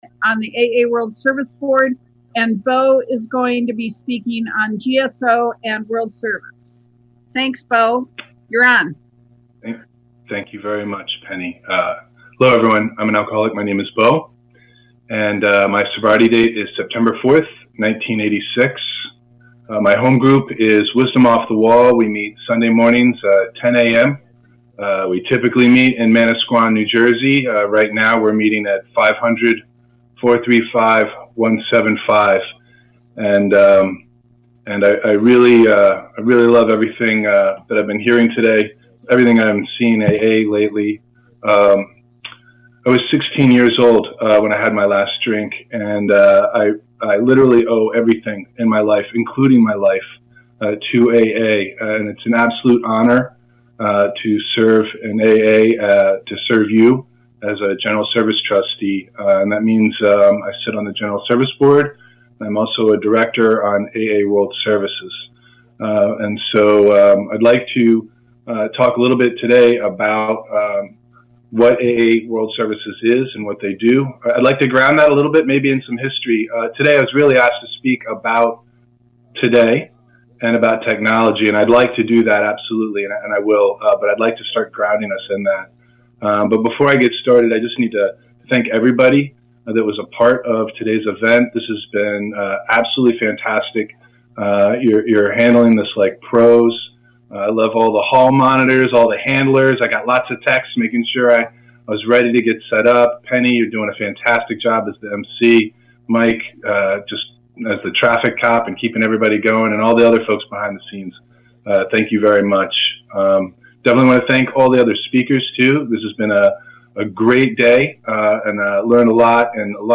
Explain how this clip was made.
AA World Service International Corona Service Conference of AA Worldwide 4-4-2020